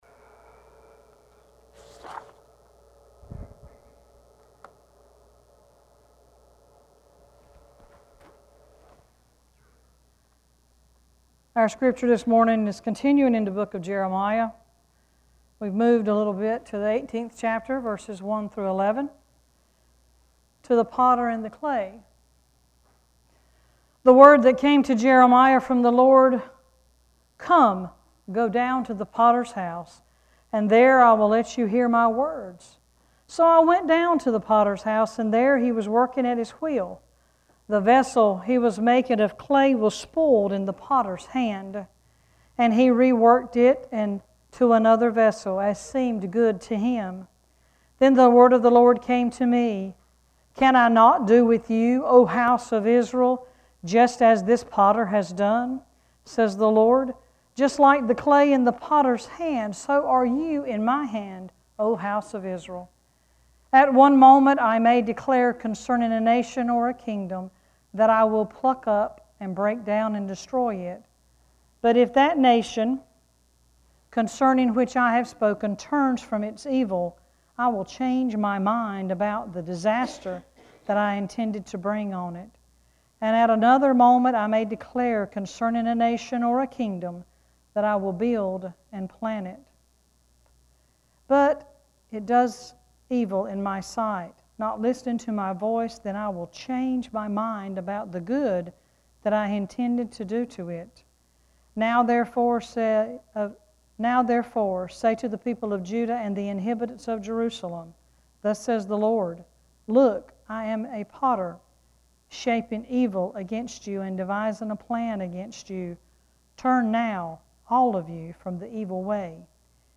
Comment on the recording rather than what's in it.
Worship Service 9-4-16: “Time for a Change”